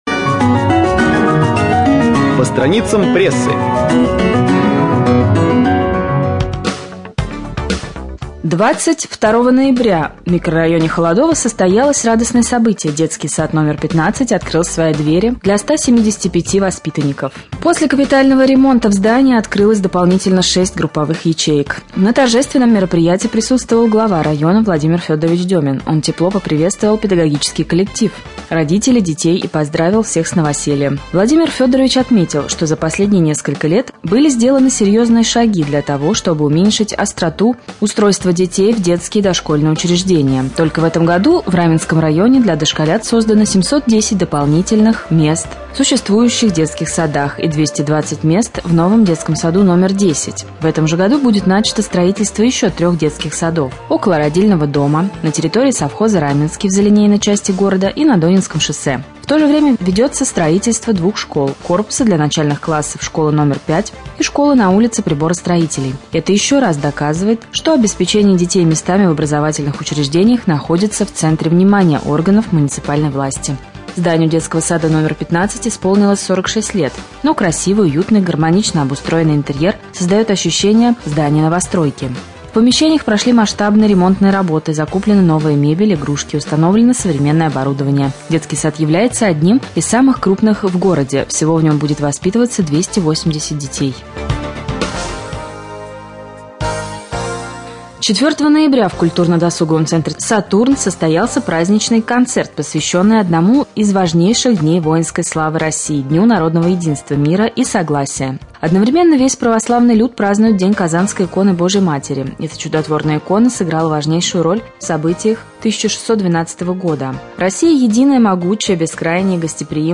Прослушать: новости 2.